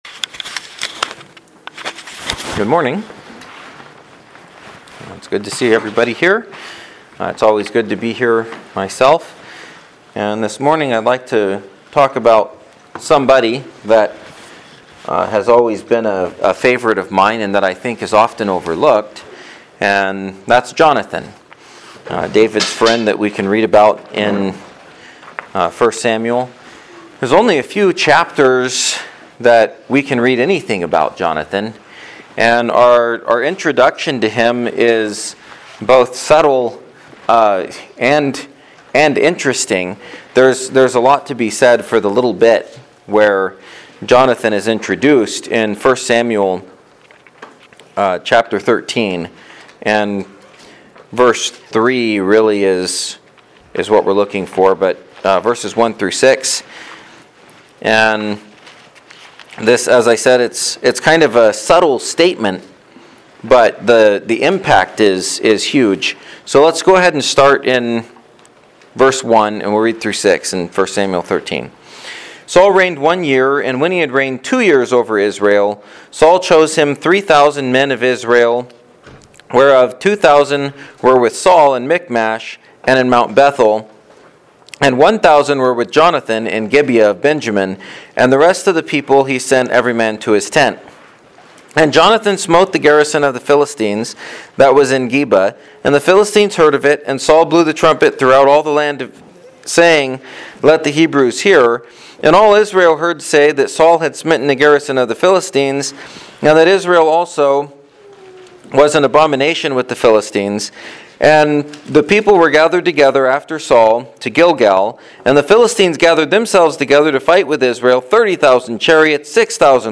2020 Sermons